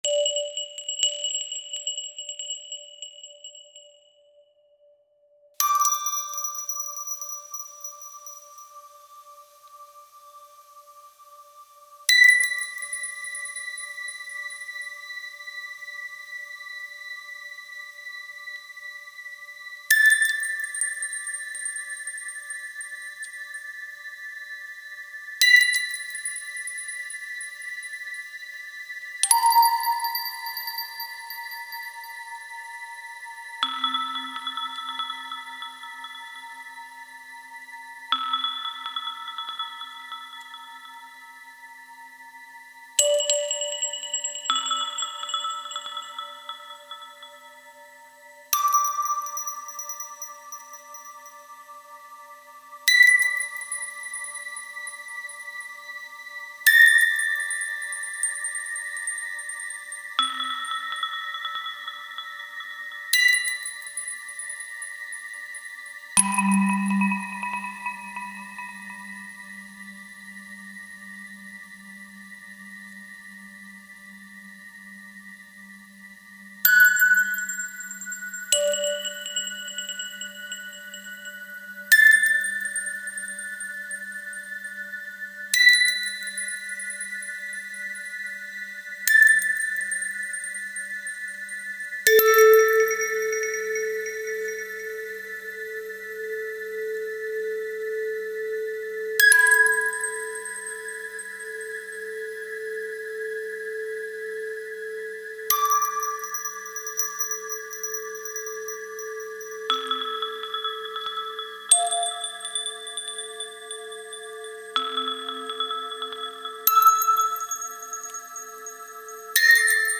German sound creator